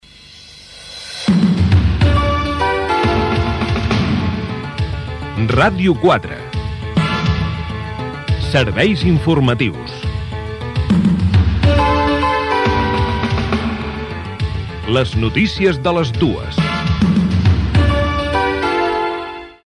Careta del programa Gènere radiofònic Informatiu